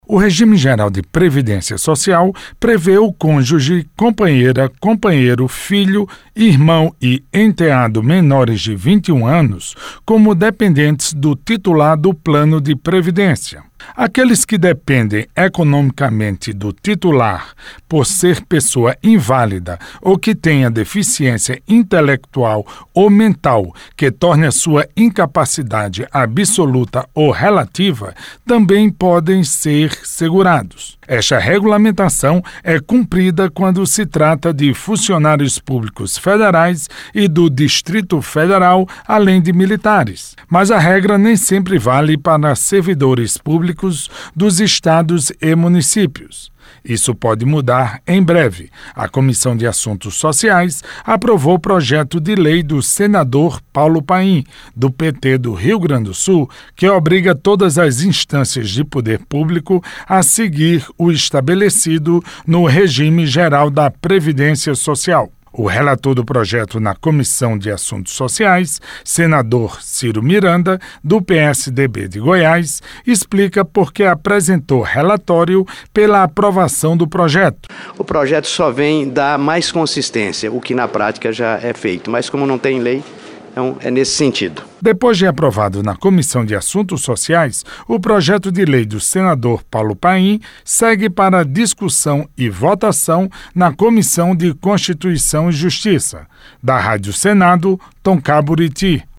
O relator do projeto na Comissão de Assuntos Sociais, senador Cyro Miranda, do PSDB de Goiás, explica porque apresentou relatório pela aprovação do projeto.